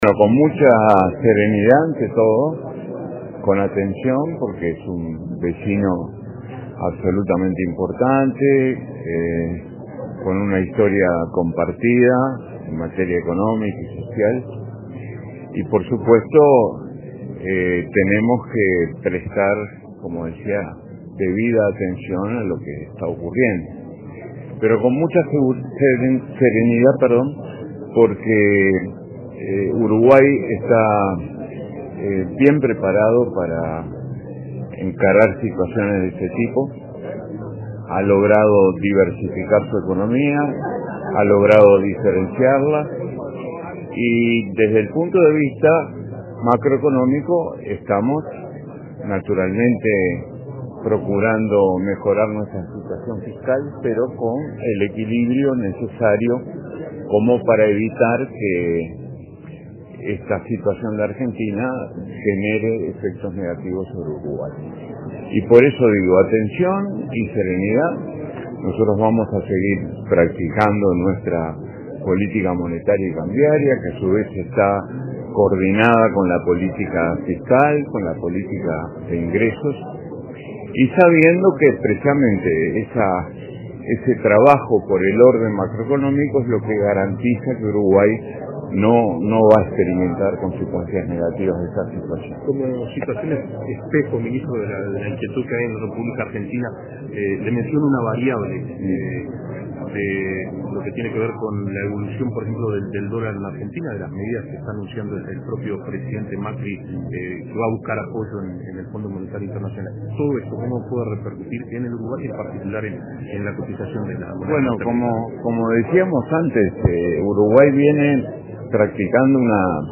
“Uruguay toma con serenidad y atención la situación económica en Argentina”, afirmó el ministro de Economía, Danilo Astori, en declaraciones a la prensa. La política de diversificación económica y diferenciación, sumado al orden macroeconómico, la solvencia financiera y su mantenimiento de grado inversor, hacen que Uruguay “esté bien parado” frente a este tipo de situaciones, explicó.